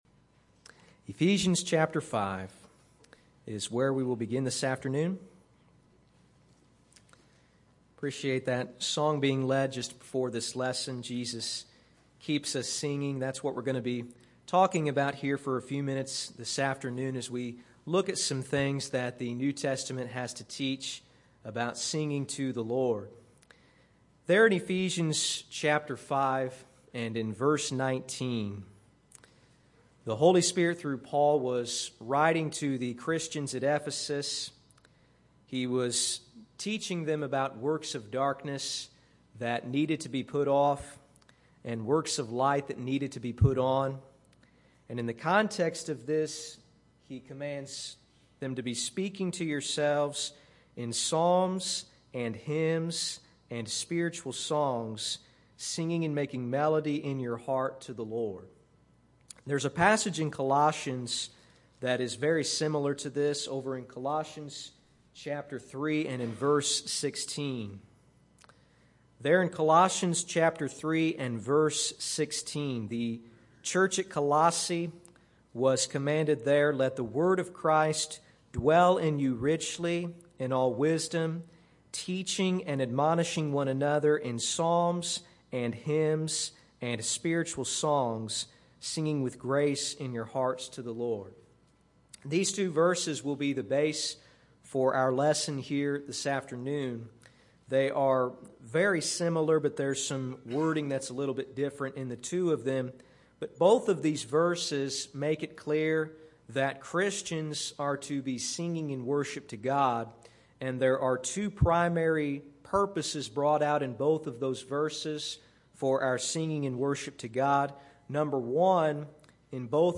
Sermons - Olney Church of Christ
Service: Sunday AM